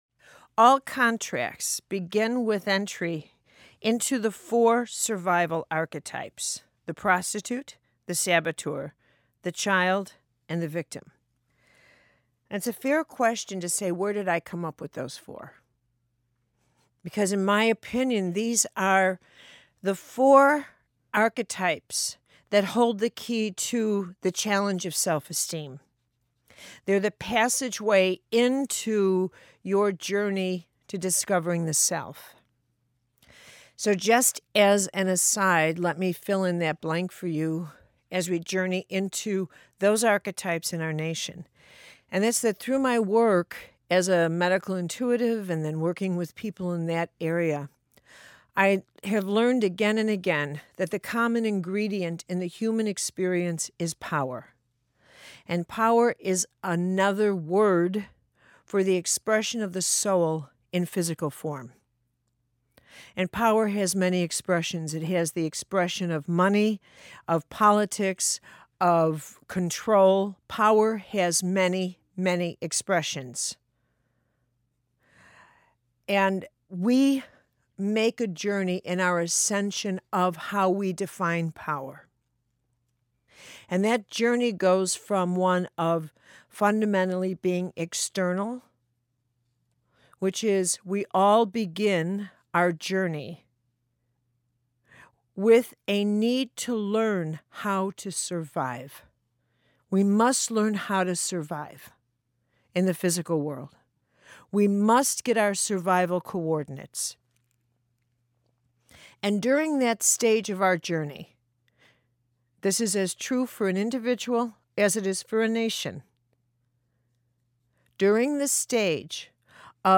We are not separate from the world we live in. This excerpt from a lecture of Carolyne Myss, is the basis and something we all need to know, being on this journey-